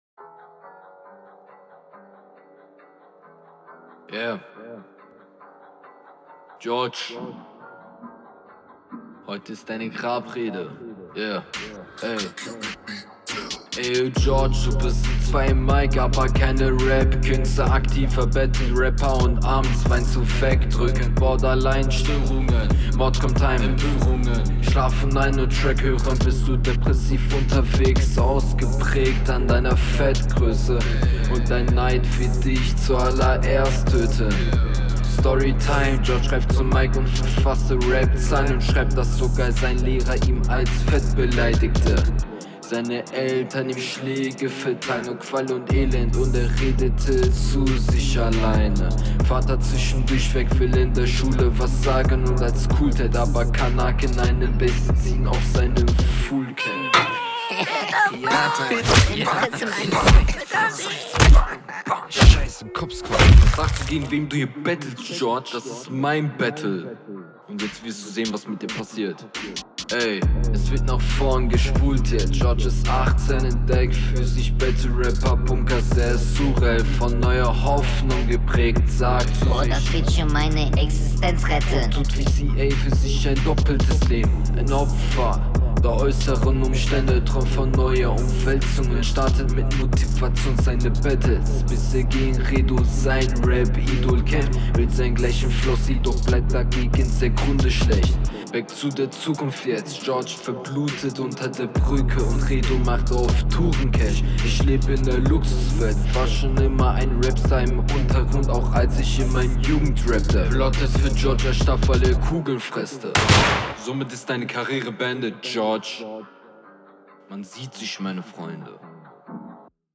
bitte nicht während der aufnahme gegen dein mic schlagen. plottwist ich starb weil ich runde …